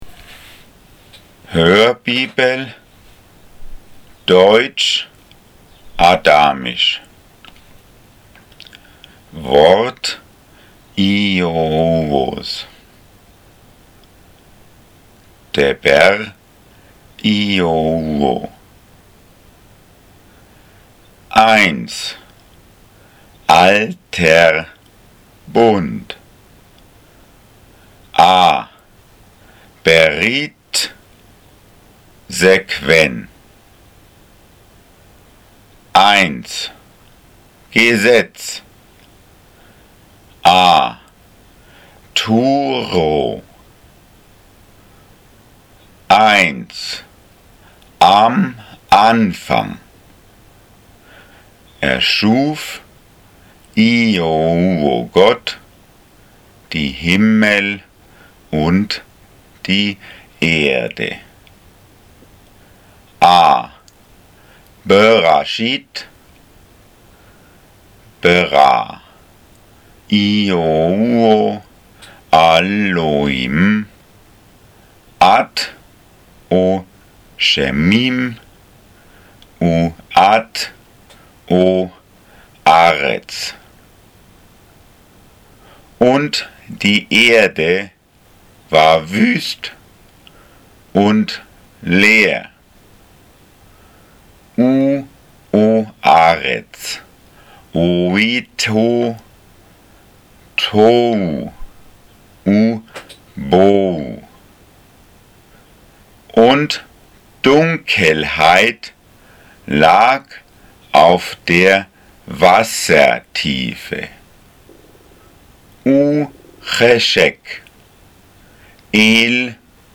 Hörbibel Deutsch Adamisch
Audiobibel_DeuAda_Gen_1_1_5.mp3